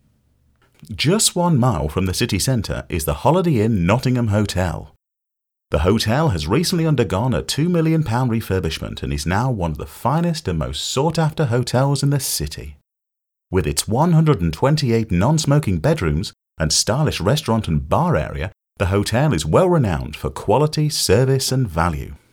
Adverts